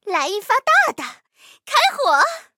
M10狼獾夜战攻击语音.OGG